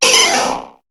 Cri de Tartard dans Pokémon HOME.